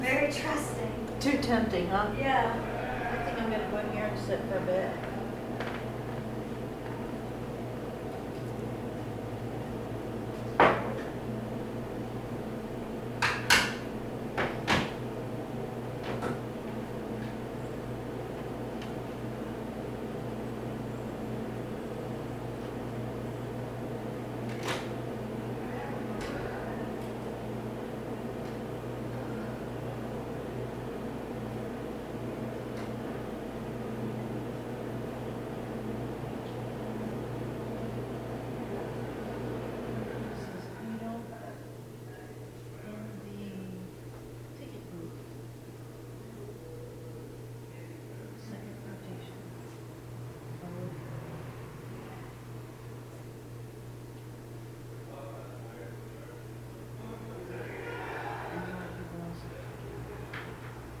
Mahanoy City, PA : Old Elks Theater
Electronic Voice Phenomenon (EVP)
• Clip 1 (0004 hrs.)During an EVP session in the background a yell could be heard…there was no report or tagging of such a yell during the investigation